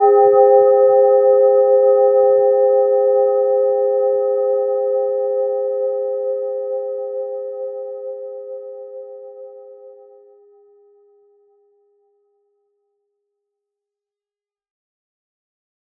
Gentle-Metallic-2-C5-p.wav